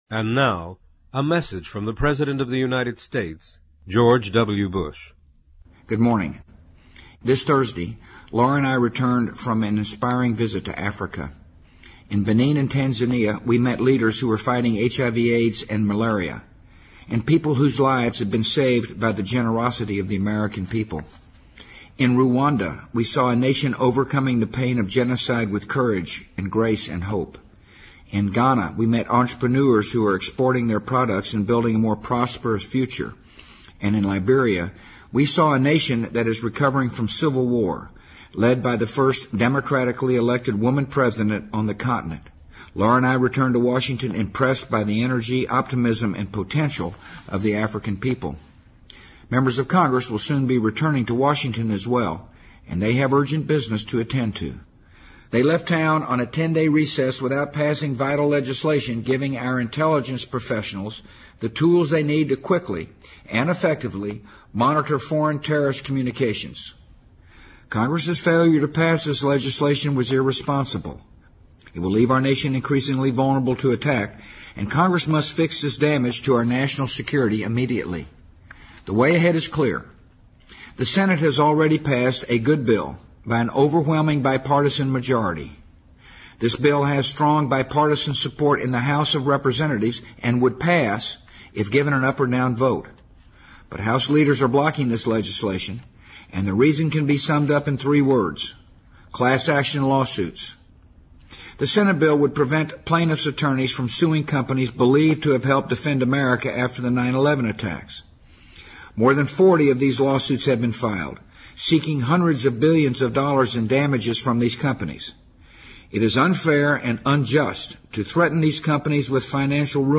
【美国总统电台演说】2008-02-23 听力文件下载—在线英语听力室